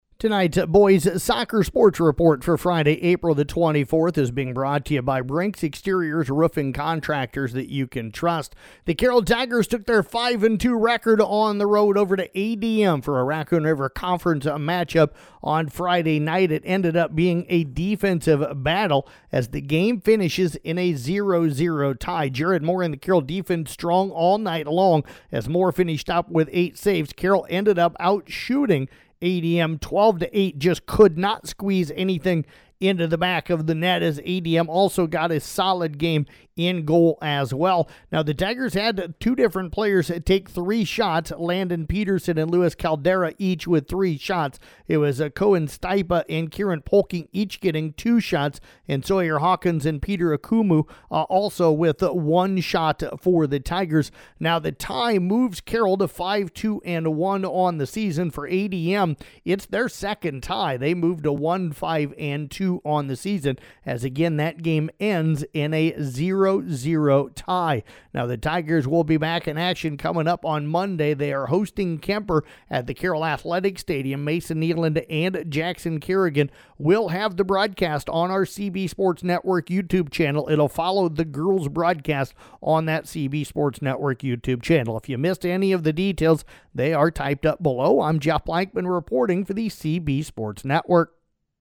Below is an audio recap of Boys Soccer for Friday, April 24th